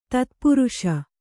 ♪ tatpuruṣa